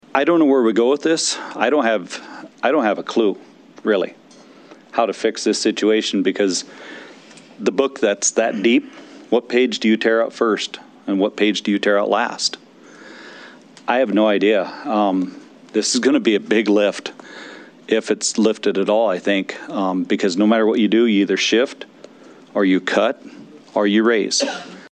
The “Study Committee on Property Tax Structure and Tax Burden” held their second meeting of the interim in Pierre.
Representative Oren Lesmeister of Parade says he is stuck about what they can do.